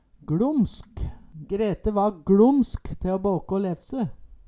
Høyr på uttala Ordklasse: Adjektiv Kategori: Karakteristikk Attende til søk